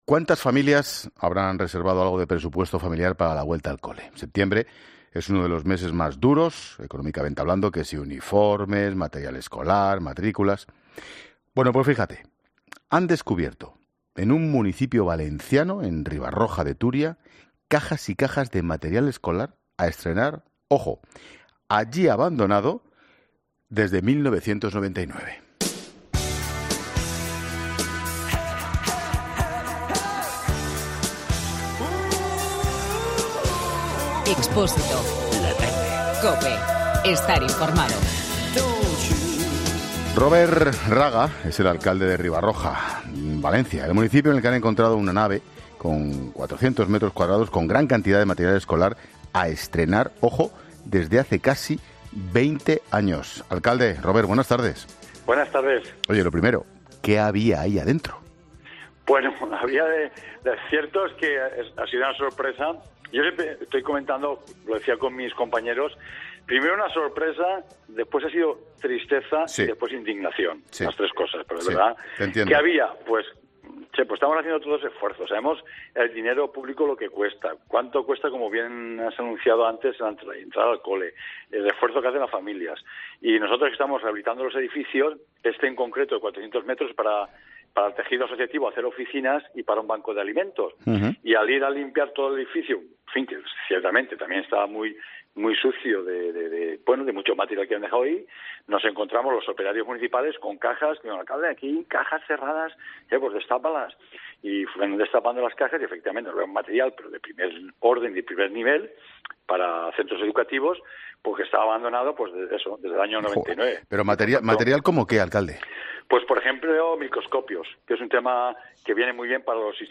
El alcalde de Riba-roja de Túria, Robert Raga, ha explicado en 'La Tarde' que “el hallazgo ha sido una sorpresa, pero ha terminado siendo una indignación”.